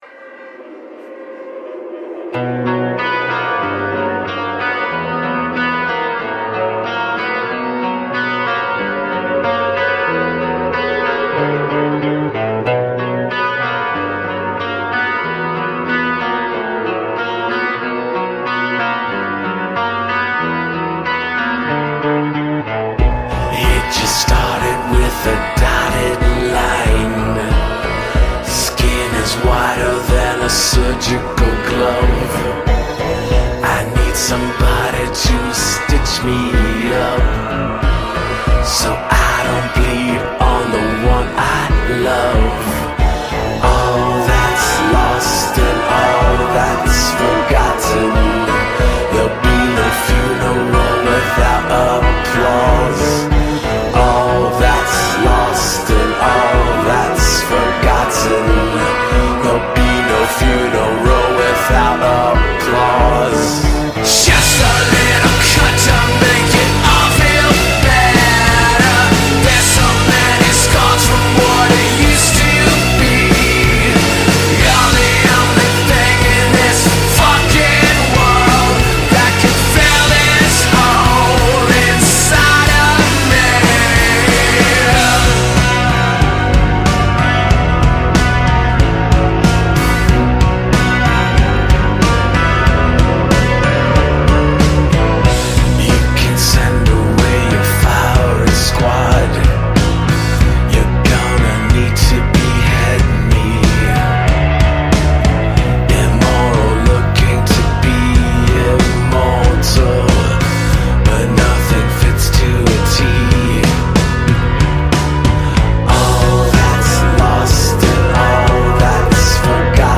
Для озабоченных музыкальными ужастиками